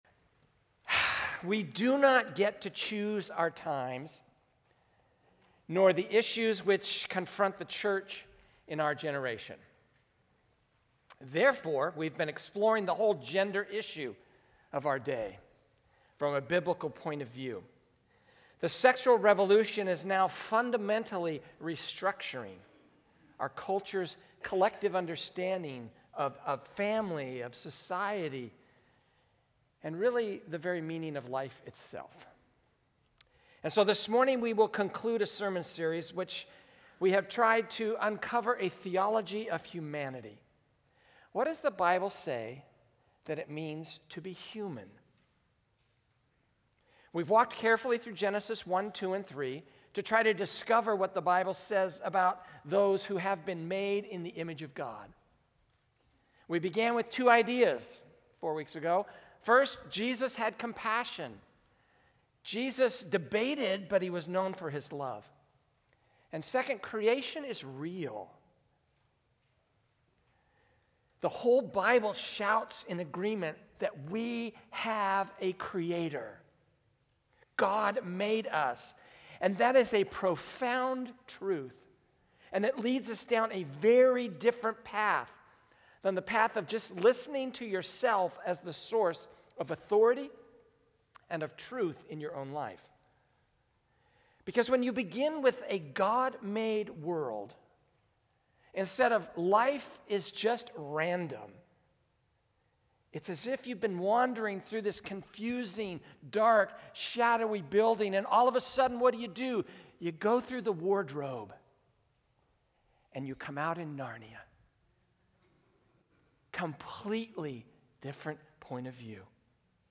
A message from the series "IMAGO DEI." So we will consider a biblical theology of humanity.